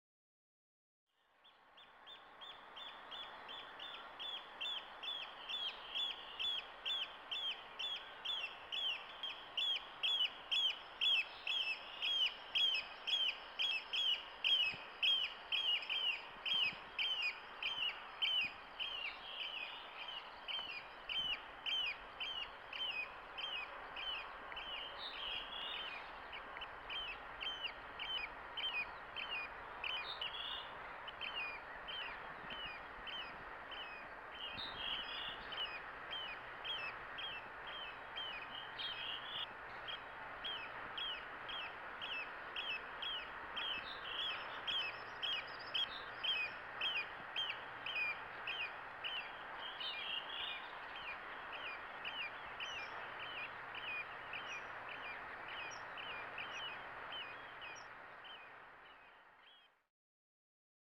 Kuuntele: Meluisat meriharakat